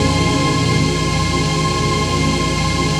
DM PAD2-37.wav